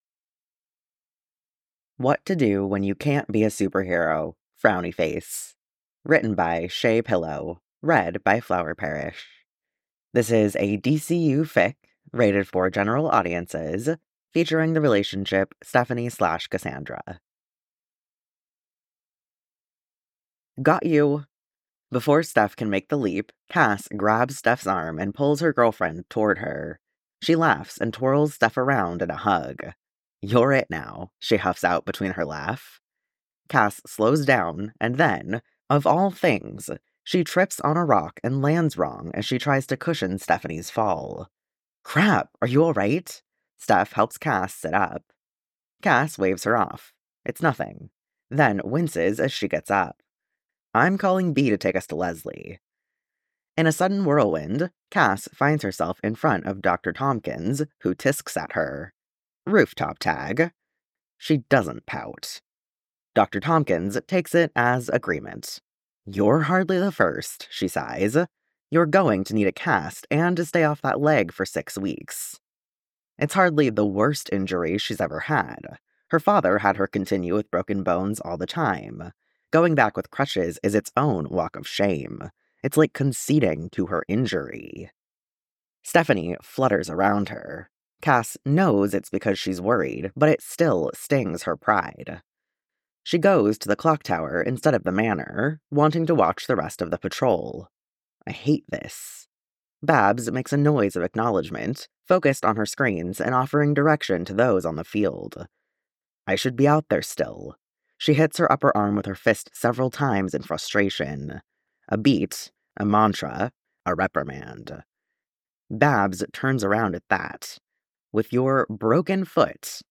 [Podfic] What to do When You Can't be a Superhero